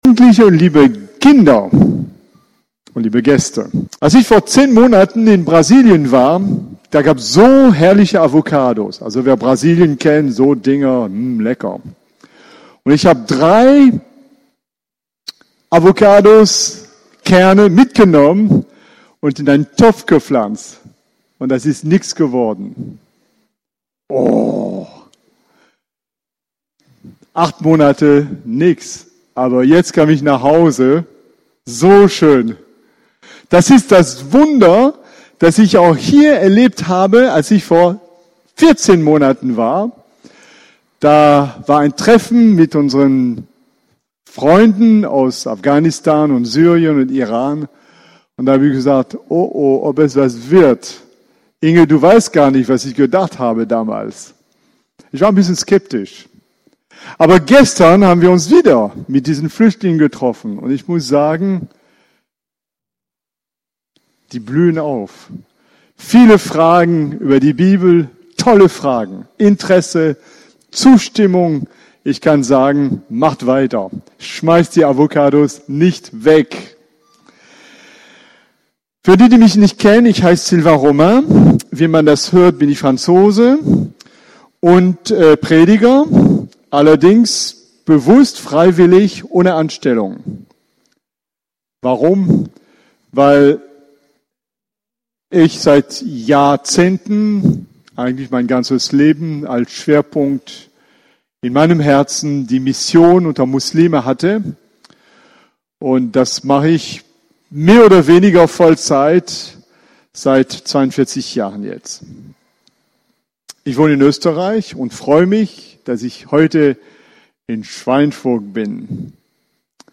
************* on 2019-09-14 - Predigten